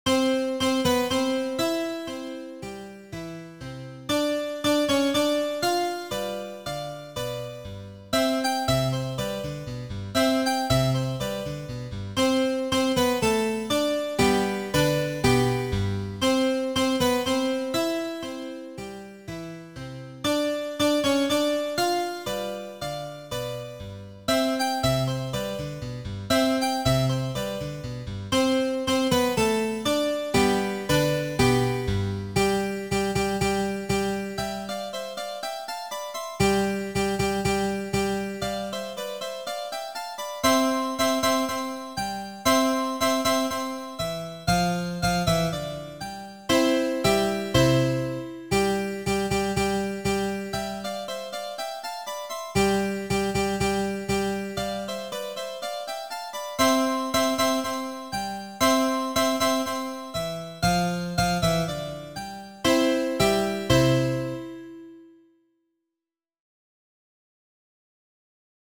eZMono/ Osnove klavirske in orgelske igre
04_Turski_mars_v_C-duru.wav